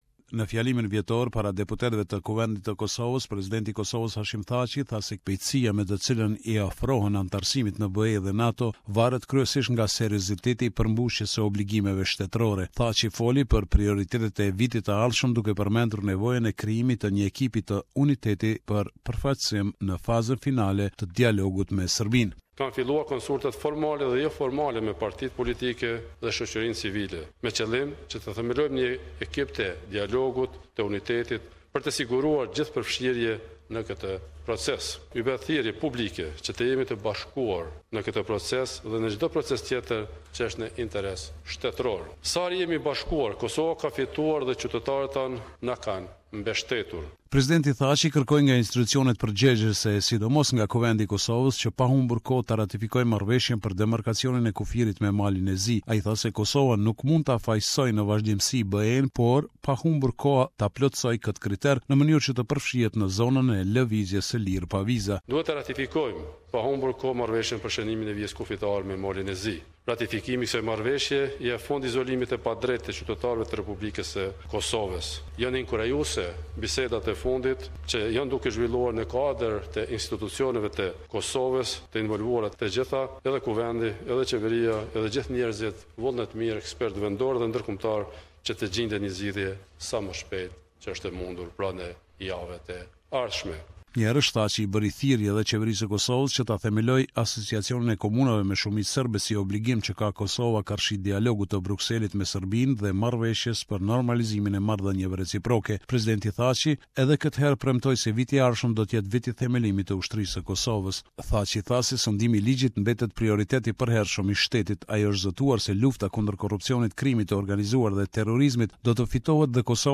This is a report summarising the latest developments in news and current affairs in Kosovo.